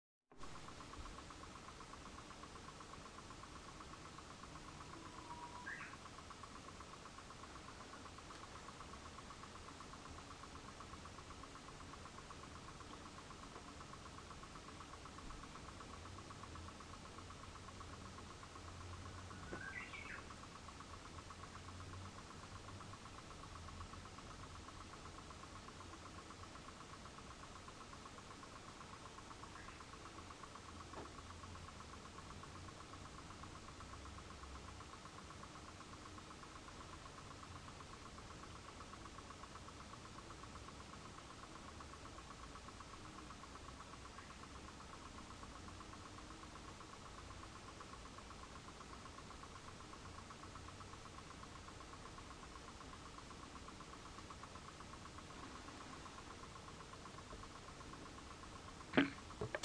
ウグイスの鳴き声です。
ウグイスが部屋のそばで鳴き始めました。
そして、ICレコーダーで録音する準備を始めました。
ほんとに、部屋のすぐ横で鳴いている感じできこえてきましたね。
ほんの僅かな鳴き声だけになっていますが音量を調節しているのが、分かって頂けると思います。
最初、何の音かなと思ったのですが目覚まし時計のそばで録音をしていました。
いゃー、目覚まし時計からはこんな音が出ていたのですね。
ウグイス_200502_0901